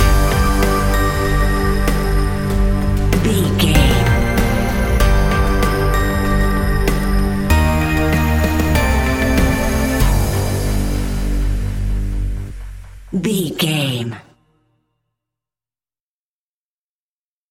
Aeolian/Minor
scary
tension
ominous
dark
haunting
eerie
synthesiser
tense
mysterious
ticking
electronic music